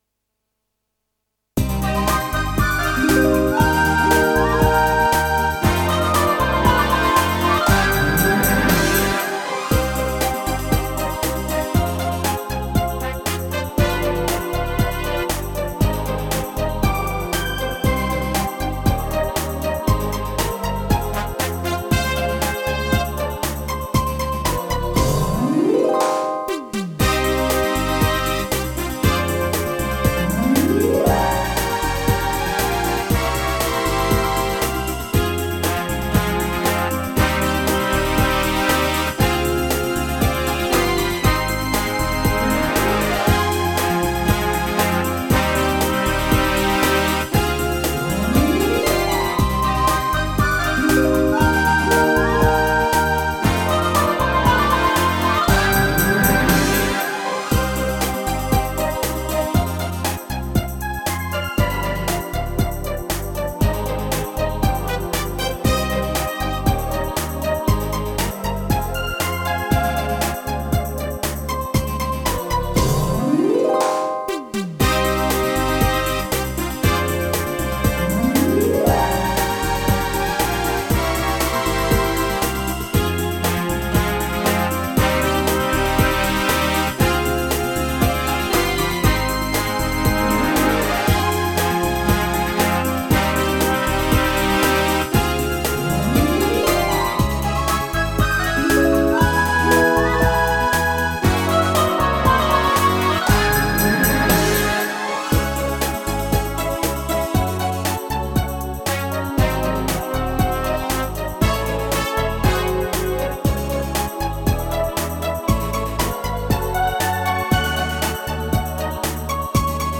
Рубрика: Поезія, Авторська пісня
Такою і повинна бути ода РІДНІЙ ЗЕМЛІ: світлою, мелодійною, щирою!!! 32 32 32